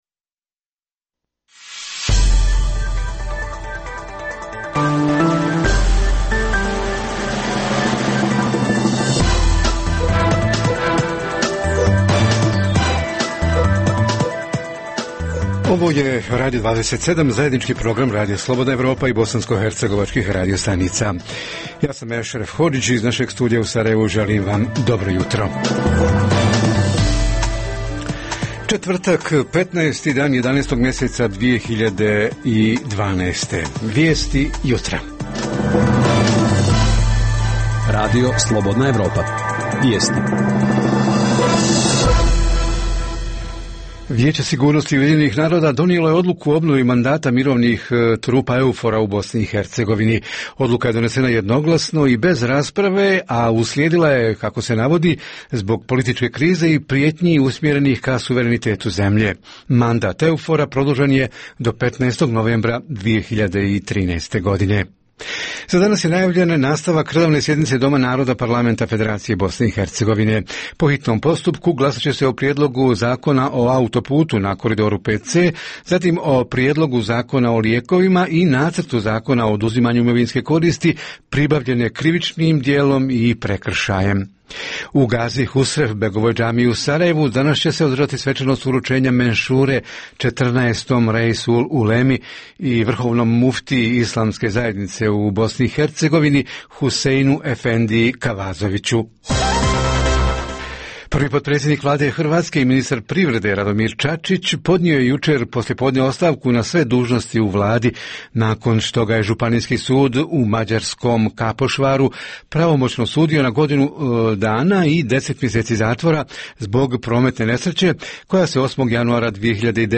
Susret uživo s Bihaćem: kako je prošla primopredaja dužnosti starog i novog načelnika općine? Iz Tuzle nam stiže najava ulične akcije prikupljanja potpisa građana radi podrške javnosti za donošenje državnog zakona o pravima žrtava torture.